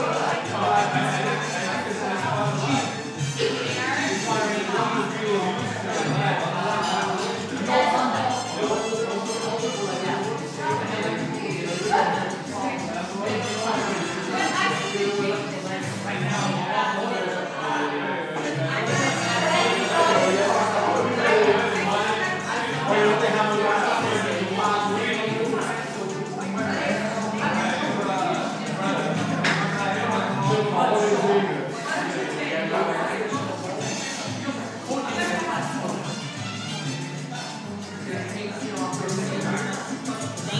Cuban restaurant Chicago